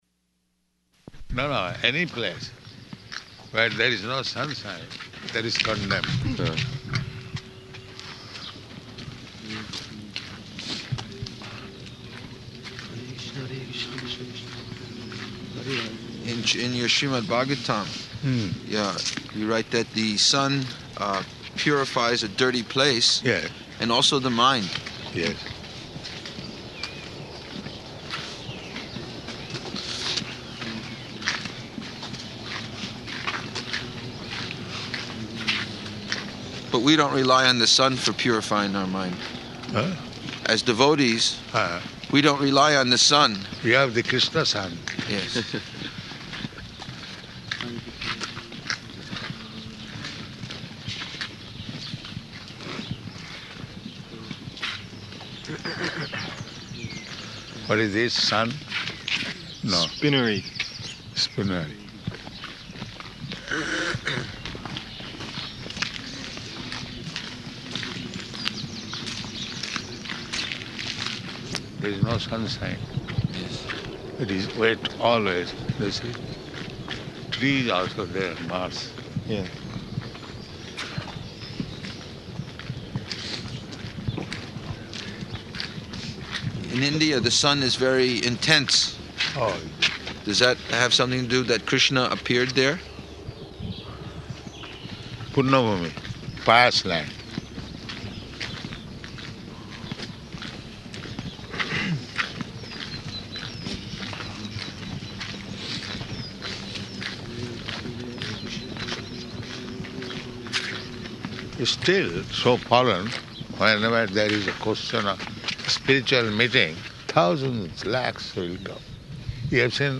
Morning Walk --:-- --:-- Type: Walk Dated: March 11th 1975 Location: London Audio file: 750311MW.LON.mp3 Prabhupāda: No, no. Any place where there is no sunshine, that is condemned.